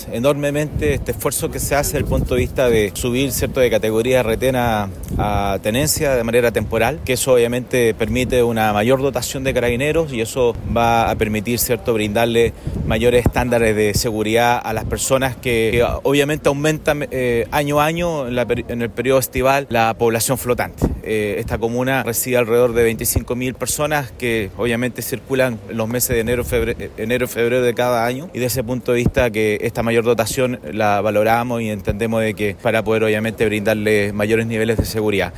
Por su parte, el alcalde, José Luis Muñoz, destacó la importancia de estos planes en época estival debido a la alta afluencia de turistas que recibe la comuna.